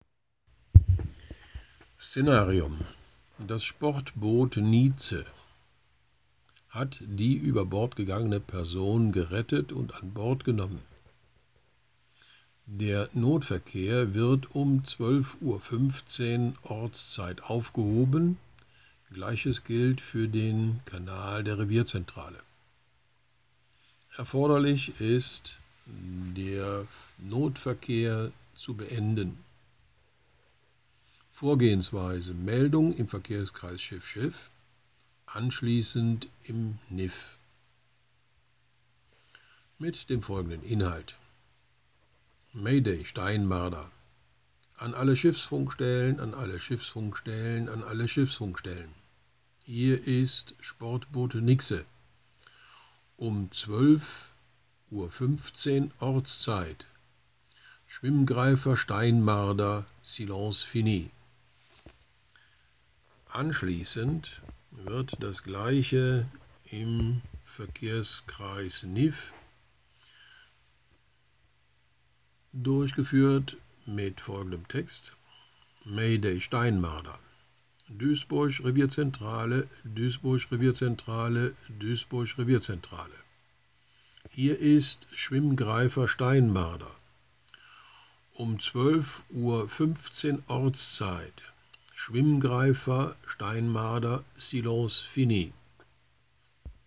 UBI - Sprechfunkbeispiele
Vor den eigentlichen Funksprüchen, gleichgültig ob Not-, Dringlichkeits- oder Sicherheitsverkehr, wird das zugehörige Szenarium dargestellt sowie die erforderlichen Maßnahmen und das Vorgehen benannt, um ein größeres Verständniss für den Funkspruch zu erreichen.